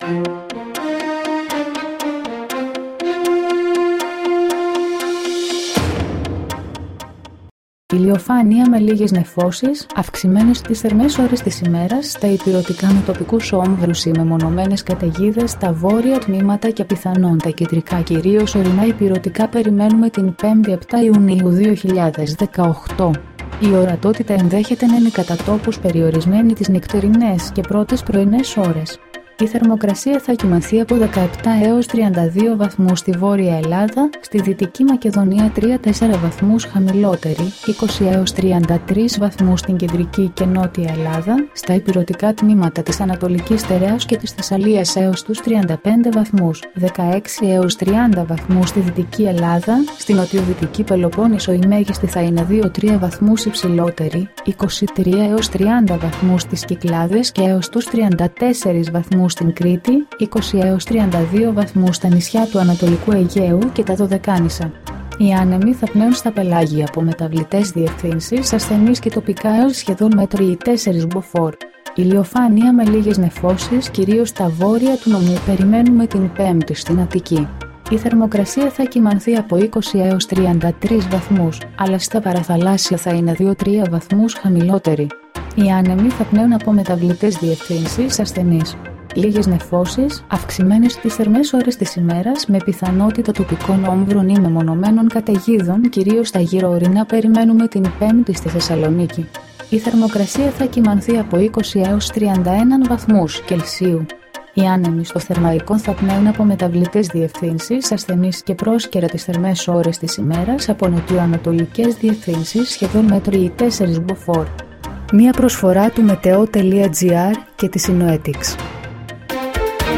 dailyforecast33.mp3